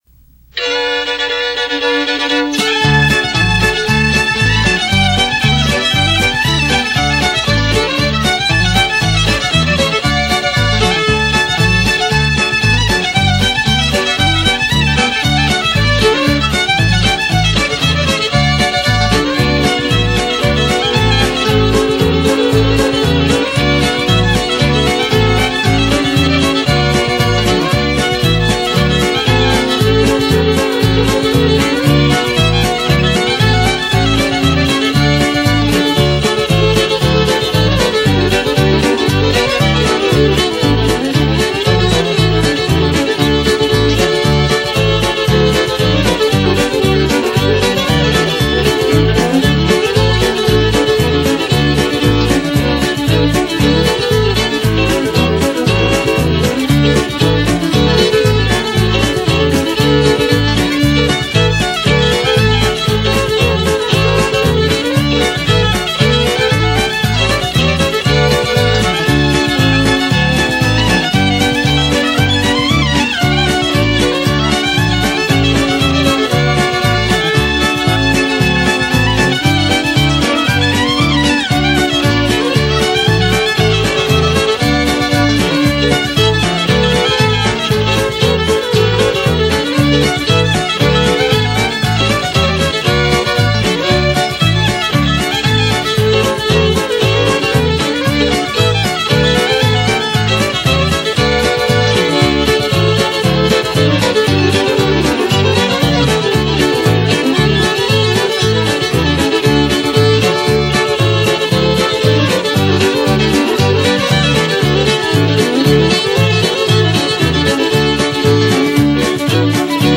fiddle
steel guitar
drums
piano